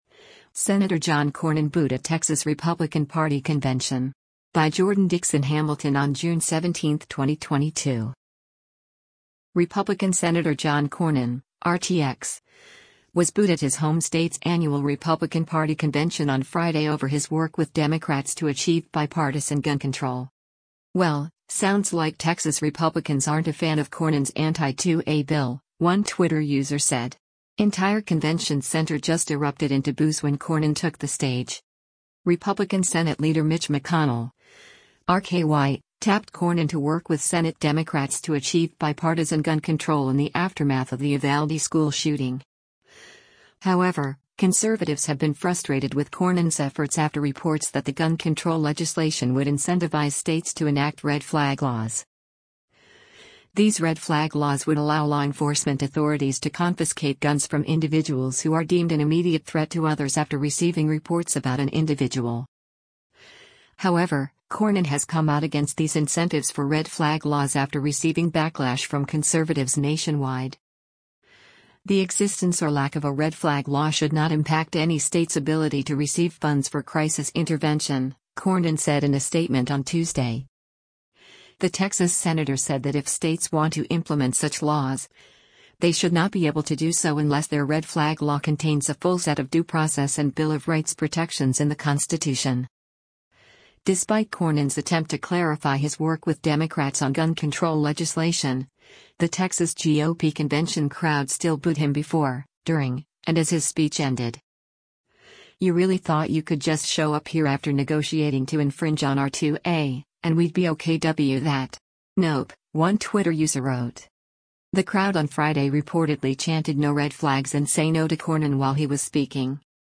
Despite Cornyn’s attempt to clarify his work with Democrats on gun control legislation, the Texas GOP convention crowd still booed him before, during, and as his speech ended.
The crowd on Friday reportedly chanted “no red flags” and “say no to Cornyn” while he was speaking.